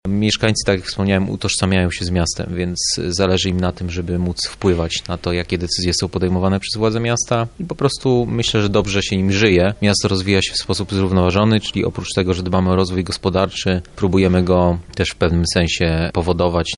To kluczowe pytanie wczorajszego panelu dyskusyjnego na Katolickim Uniwersytecie Lubelskim.